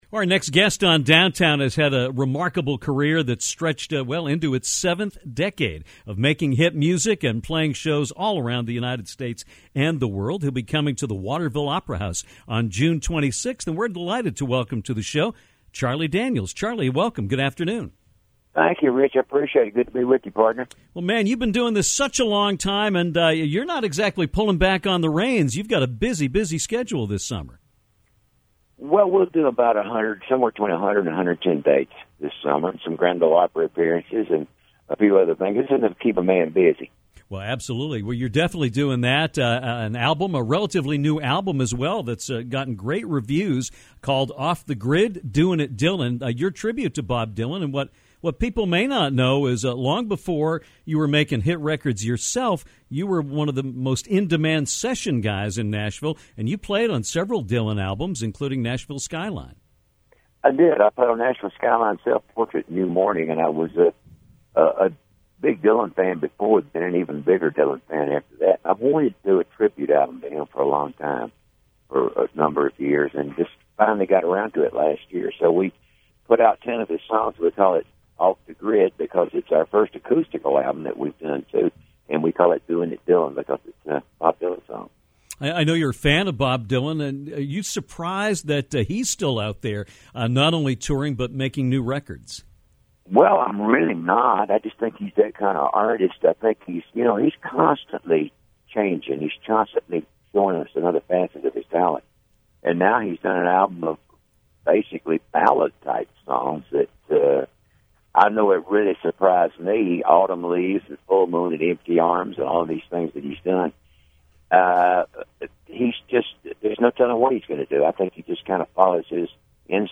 Legendary singer and musician Charlie Daniels joined Downtown to talk about his career and upcoming date in Maine. Daniels talked about some of his early work and also his songs that have patriotic ties to them.